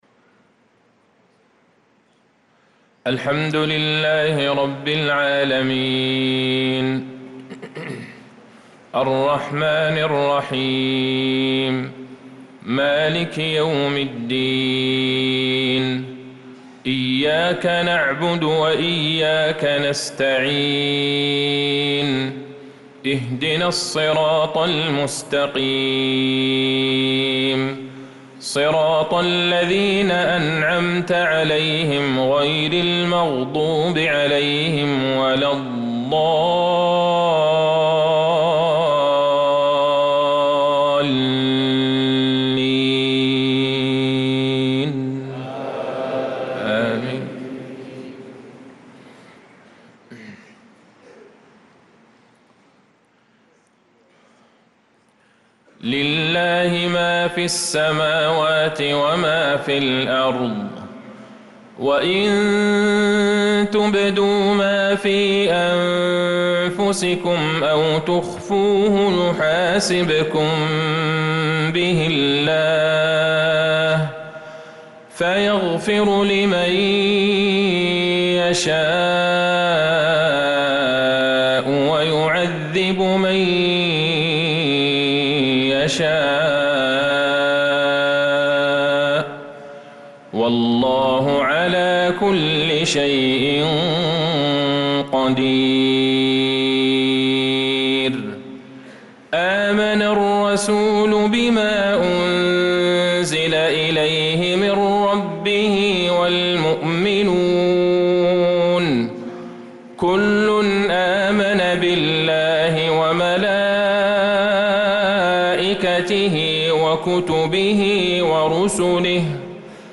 صلاة المغرب للقارئ عبدالله البعيجان 17 ذو الحجة 1445 هـ
تِلَاوَات الْحَرَمَيْن .